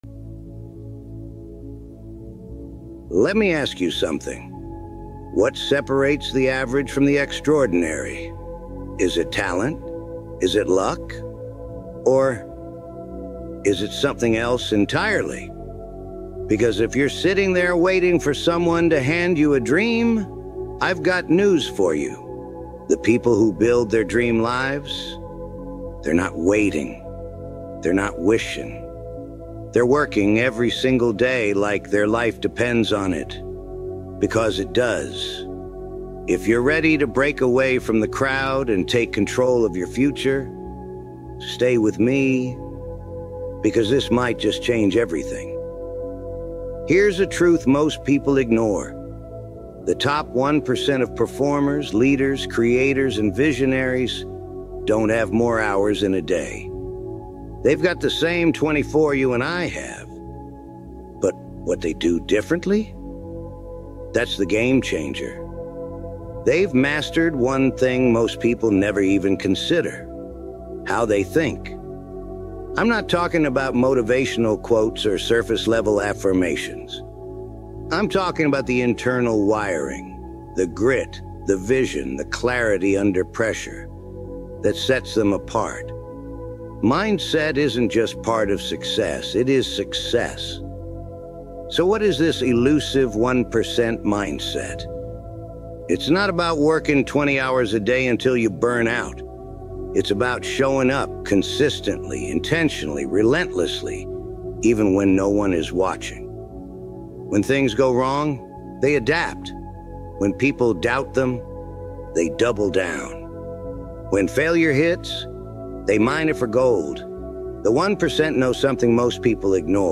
The 1% Mindset | Powerful Motivational Speech